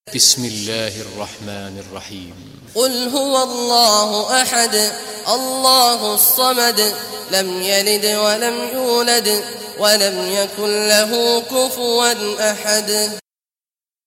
Surah Ikhlas Recitation by Sheikh Awad al Juhany
Surah Ikhlas, listen or play online mp3 tilawat / recitation in Arabic in the beautiful voice of Sheikh Abdullah Awad al Juhany.